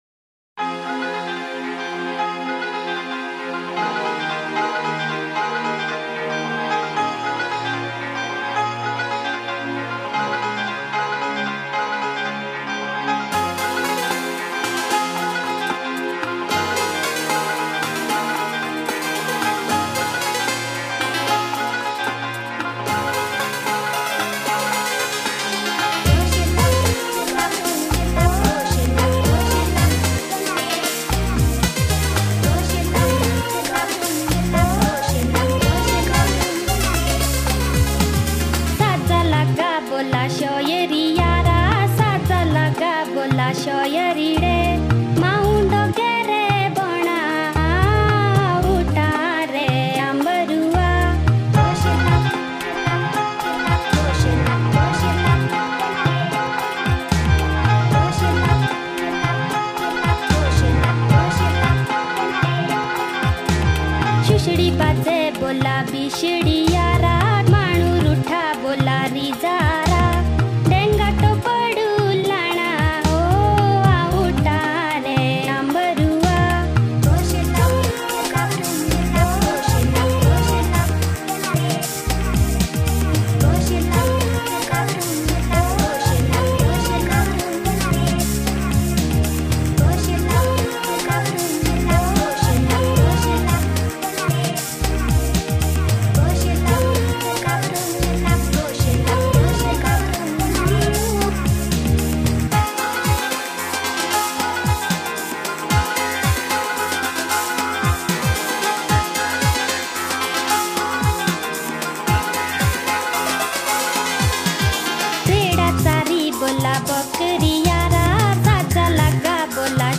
加麦兰及其他